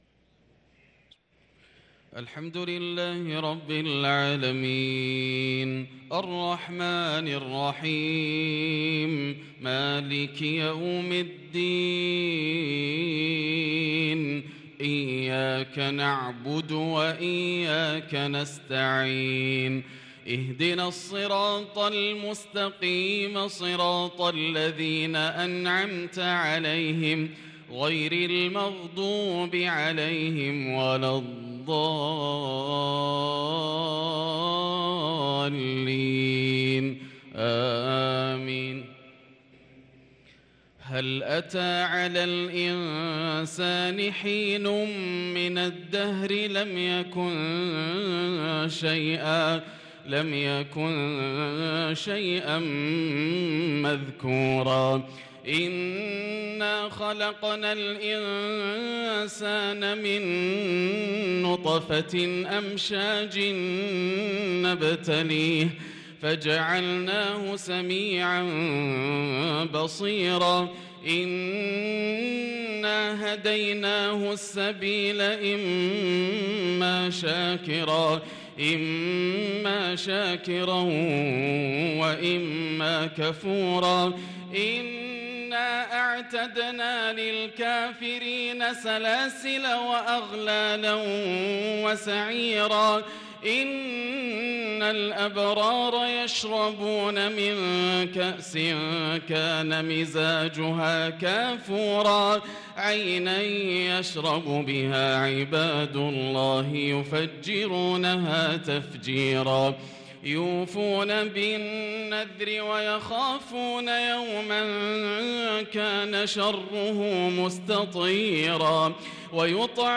صلاة العشاء للقارئ ياسر الدوسري 14 ذو الحجة 1443 هـ
تِلَاوَات الْحَرَمَيْن .